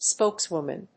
/ˈspoˌkswʊmʌn(米国英語), ˈspəʊˌkswʊmʌn(英国英語)/
アクセント・音節spókes・wòman